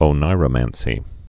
(ō-nīrə-mănsē)